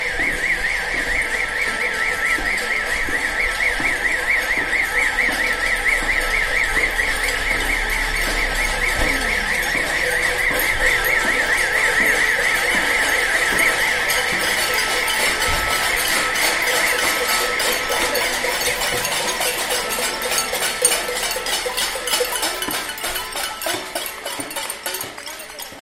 En esta concentración han participado un centenar de personas, la mayor parte de ellas identificadas con chalecos amarillos y con cacerolas que han golpeado durante más de un cuarto de hora para hacer ver el hartazgo de los comerciantes riojanos: